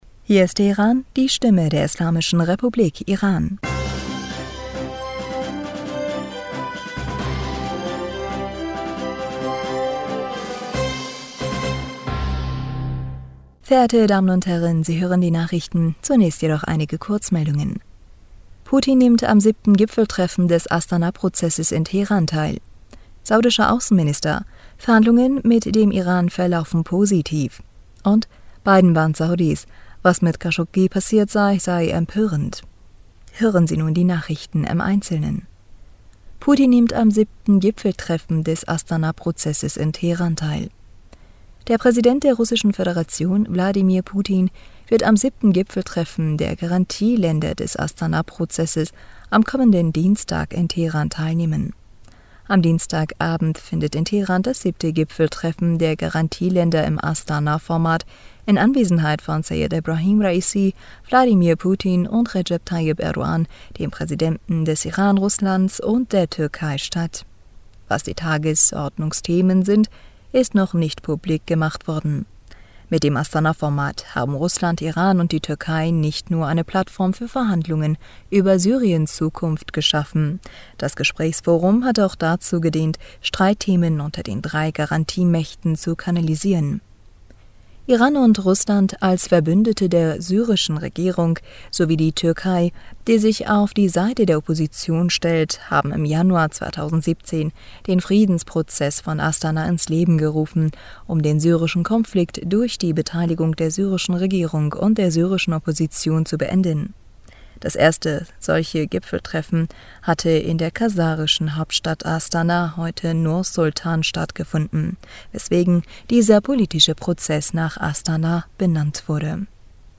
Nachrichten vom 17. Juli 2022
Die Nachrichten von Sonntag, dem 17. Juli 2022